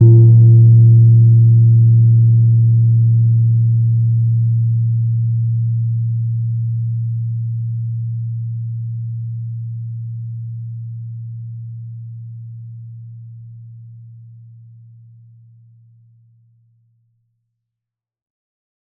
Acoustic
jing_cotton_ord-A0-pp.wav